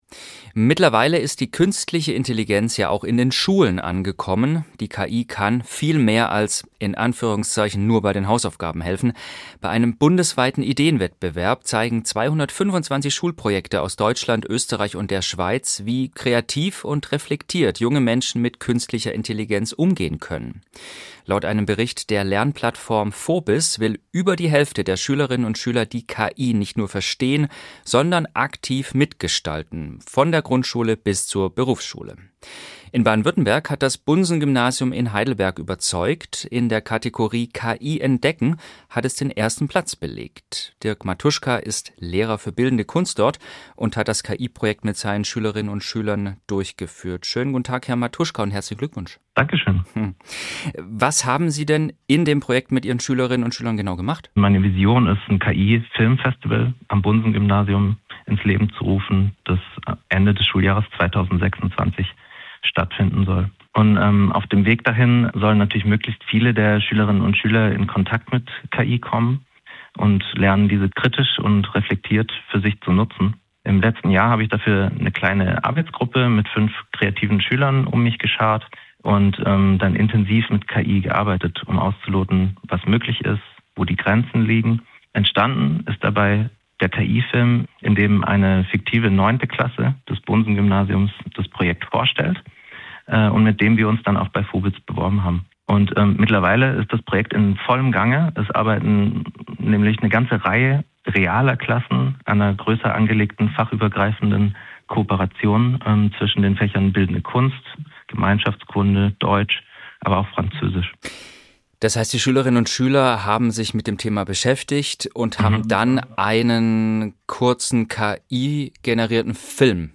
im Gespräch mit